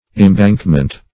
Search Result for " imbankment" : The Collaborative International Dictionary of English v.0.48: Imbankment \Im*bank"ment\, n. The act of surrounding with a bank; a bank or mound raised for defense, a roadway, etc.; an embankment.